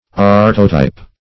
artotype - definition of artotype - synonyms, pronunciation, spelling from Free Dictionary Search Result for " artotype" : The Collaborative International Dictionary of English v.0.48: Artotype \Ar"to*type\, n. [Art + type.] A kind of autotype.